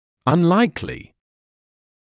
[ʌn’laɪklɪ][ан’лайкли]меловероятно; маловероятный, неправдоподобный